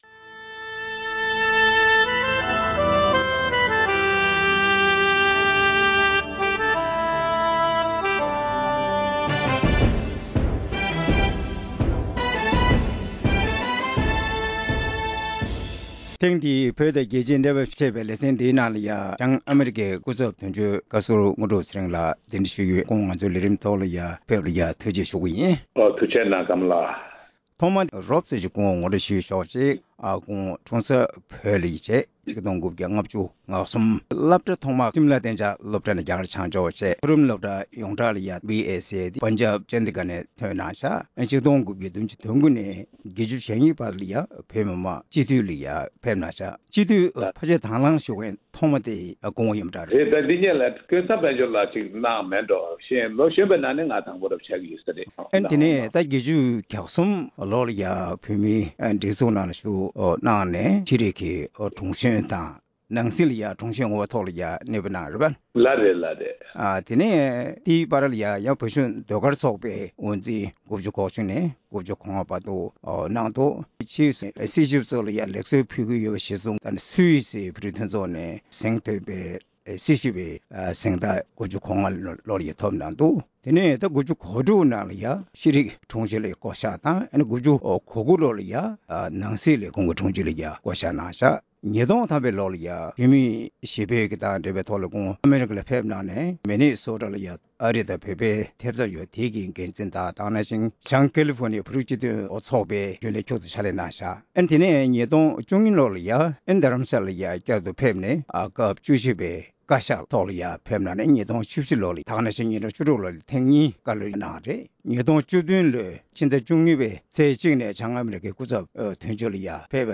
གླེང་མོལ་ཞུས་པ་ཞིག་གསན་གནང་གི་རེད།